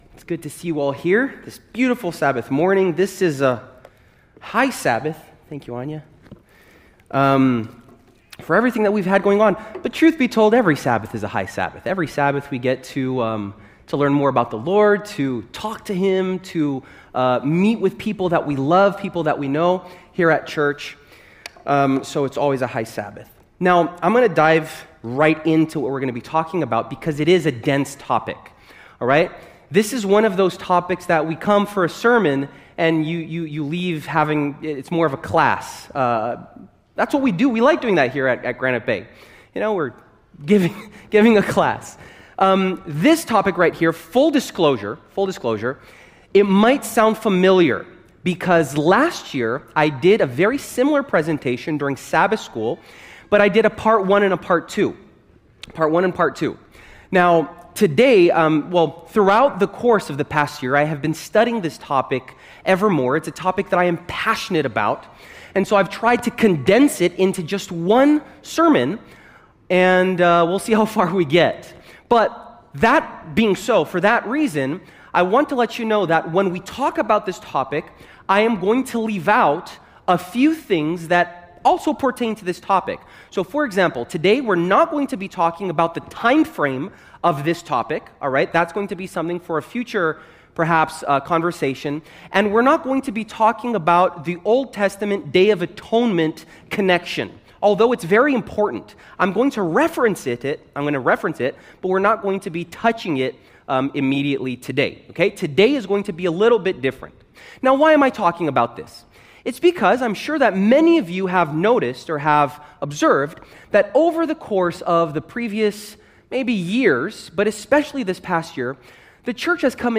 Join us for this provoking sermon that invites us to reflect on God's justice, mercy and hope.